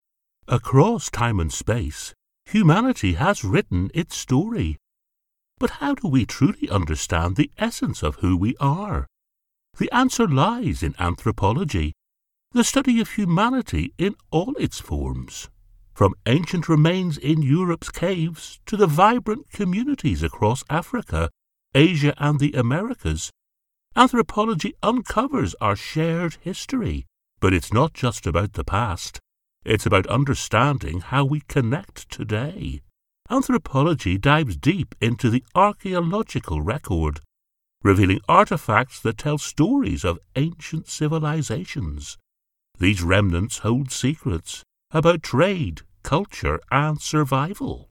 Foreign & British Male Voice Over Artists & Actors
English (Irish)
Adult (30-50) | Older Sound (50+)